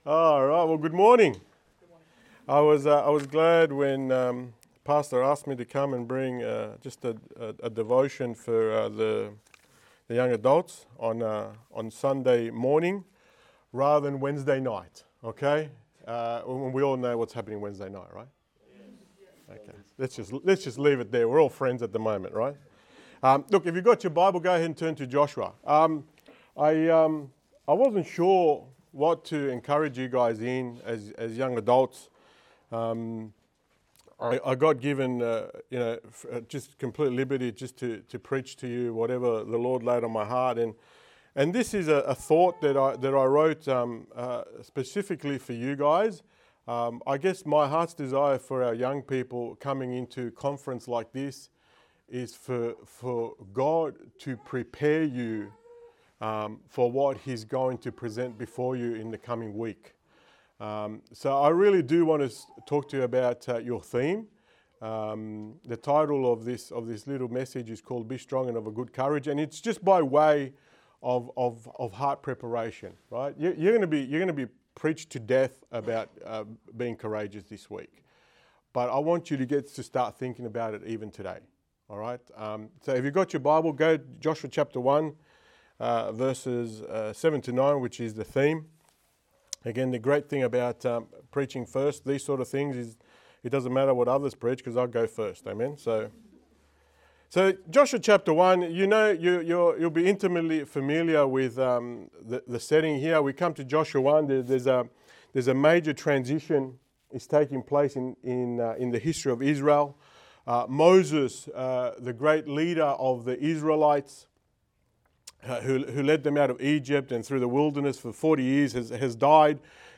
Sermons | Good Shepherd Baptist Church
Wed AM Teens Session Leadership Conference 2025